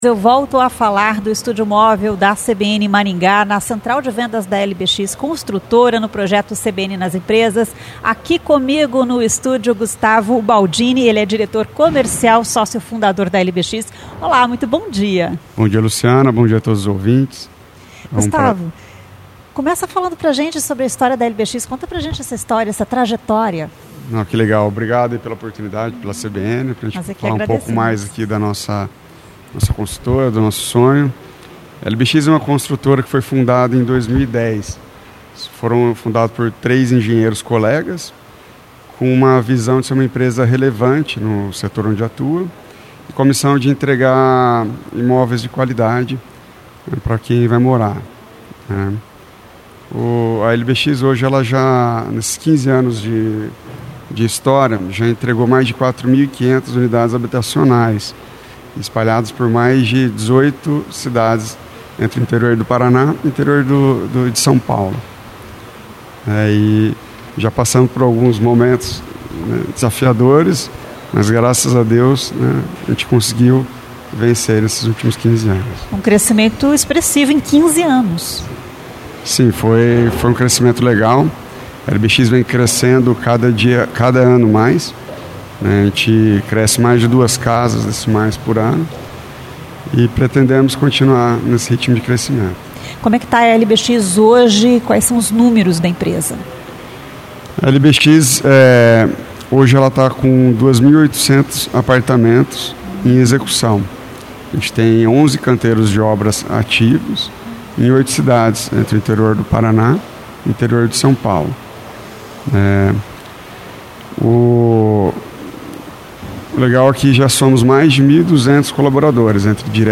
A entrevista foi realizada no estúdio móvel instalado na central de vendas da LBX Construtora, dentro do projeto CBN nas Empresas.